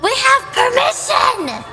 Worms speechbanks
Flawless.wav